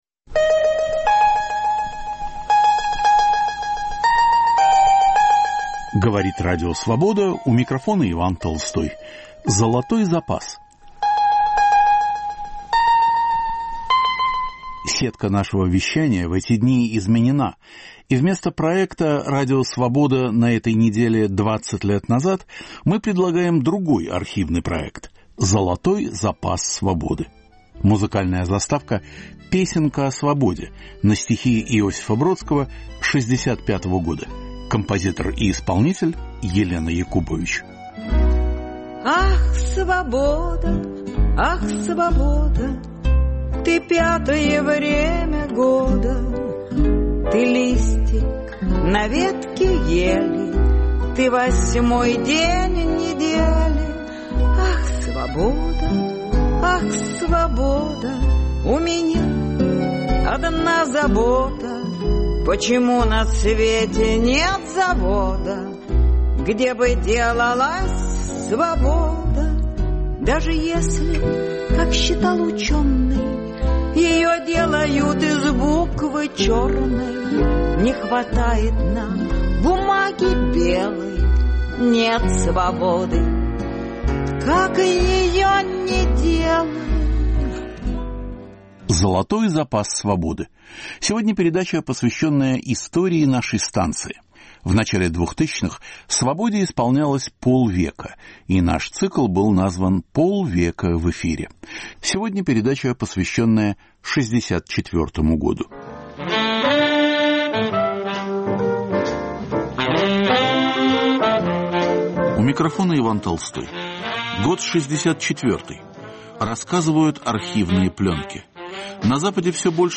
Рассказывают архивные пленки: 75 лет Анне Ахматовой, снятие Никиты Хрущева, суд над Иосифом Бродским. Звучат песни в исполнении сотрудников "Свободы".